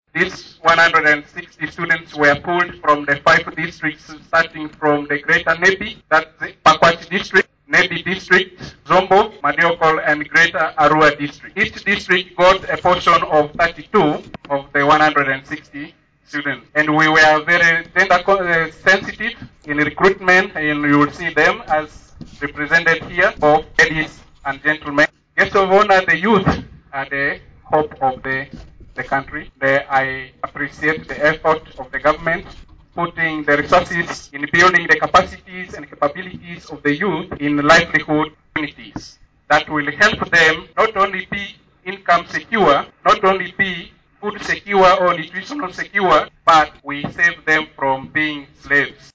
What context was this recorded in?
During the graduation ceremony on Friday 20th/01/2023 at the college